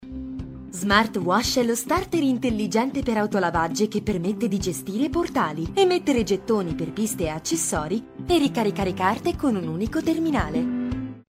意大利语样音试听下载